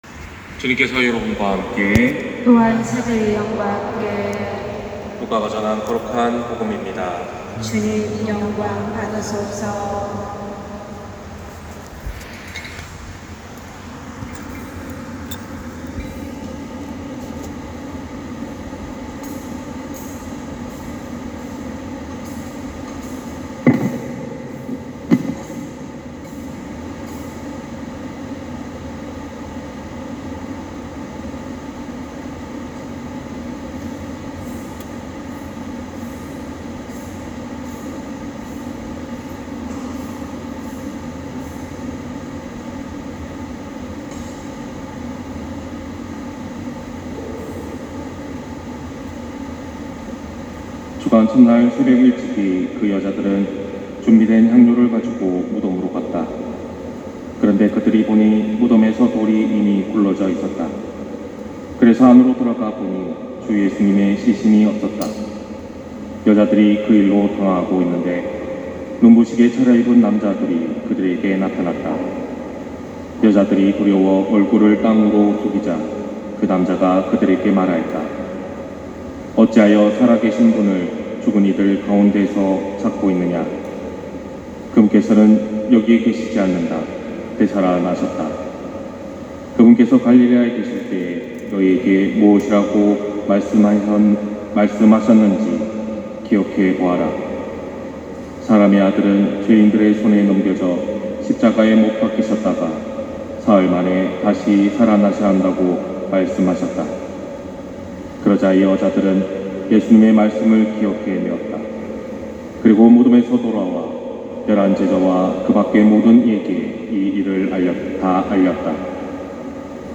250419 신부님 강론말씀